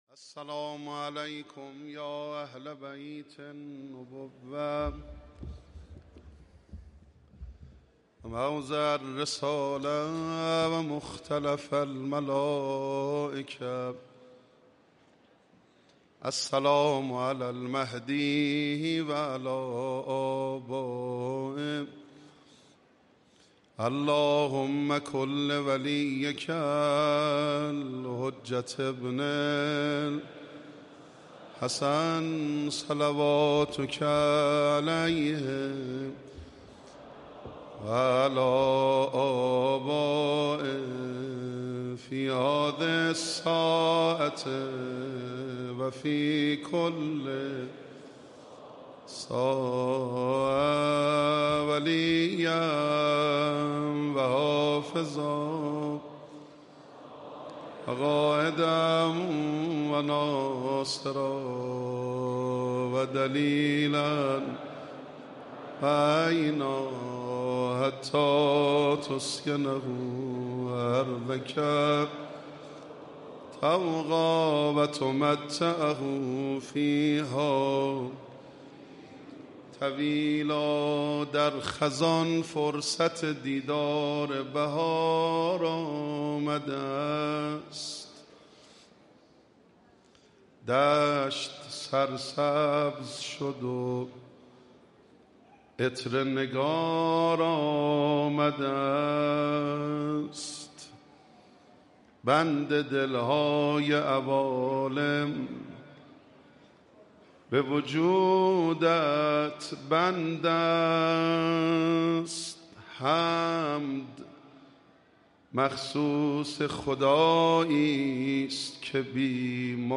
به گزارش خبرنگار فرهنگی خبرگزاری تسنیم، دومین شب از مراسم عزاداری ایام شهادت حضرت فاطمه‌زهرا سلام‌الله‌علیها شنبه 1 آبان 1404 با حضور رهبر انقلاب اسلامی و هزاران نفر از قشرهای مختلف مردم در حسینیه امام خمینی (ره) برگزار شد.
مرثیه خوانی و مداحی